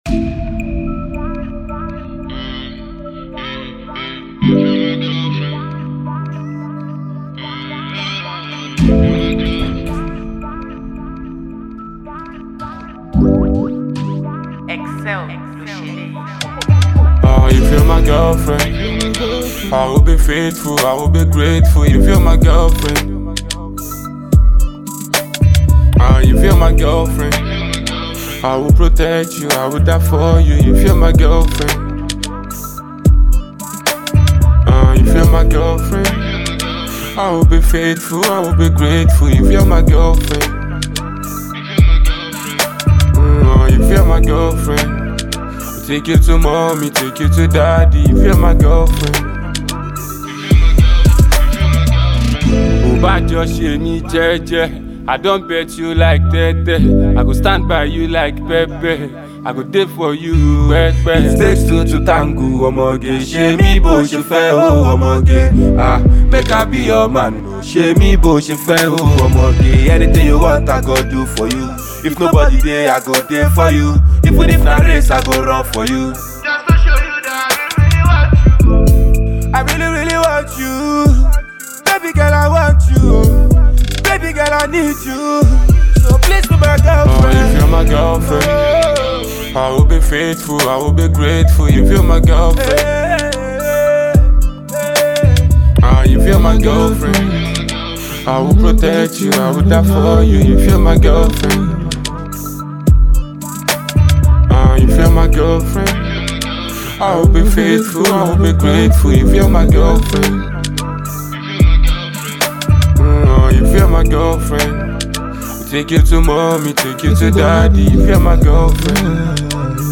is a smooth and sweet love jam made for the ladies.
It’s a romantic song with catchy lines and a cool beat